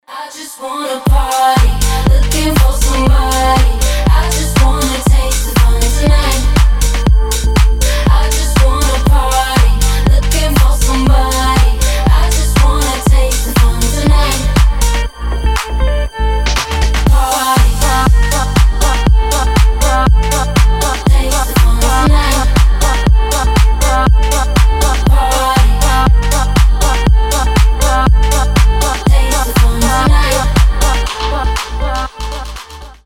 Dance Pop
house